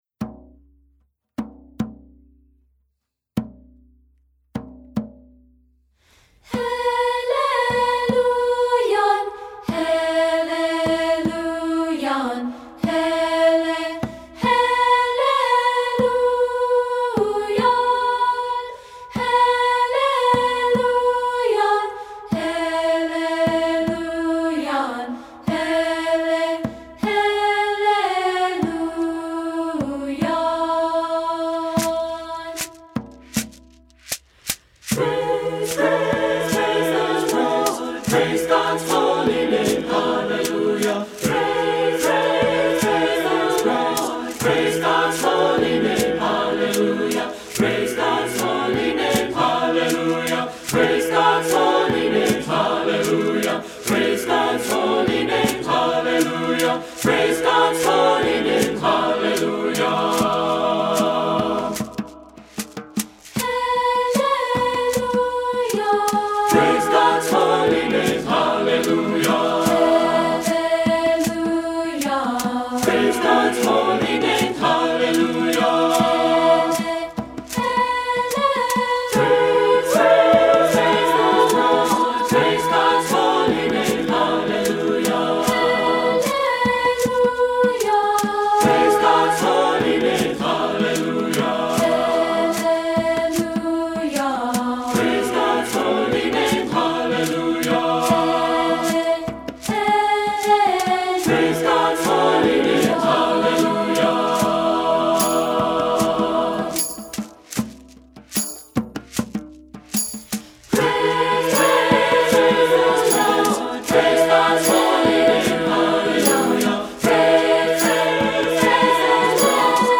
Voicing: SATB and Unison